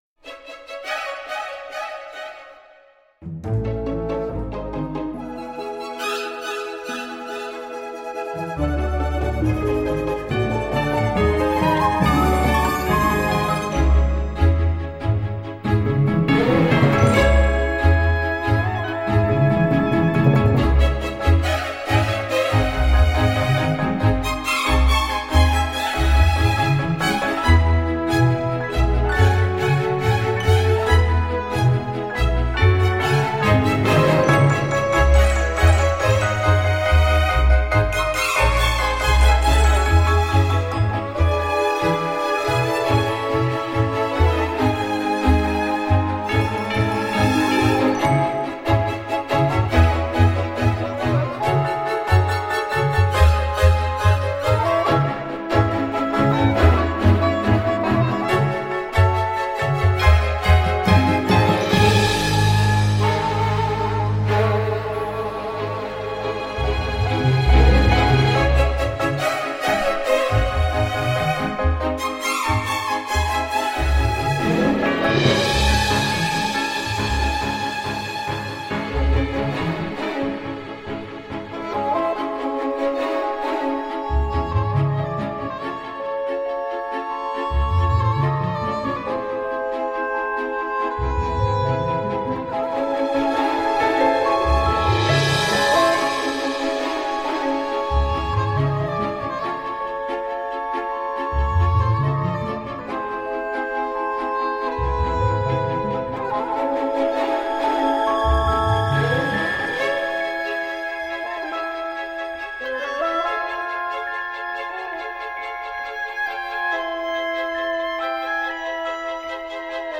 belle musique, mystérieuse, fantaisiste et énergique